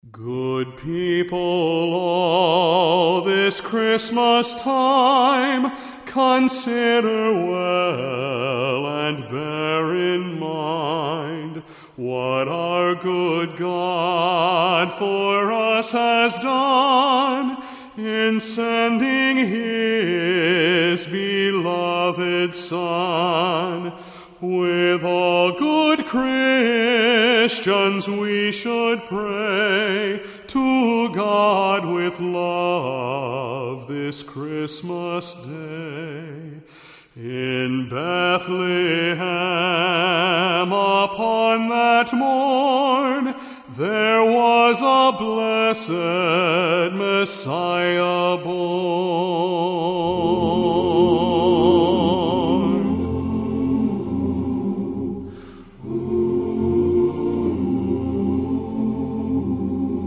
Performers: SMS Men's Chorus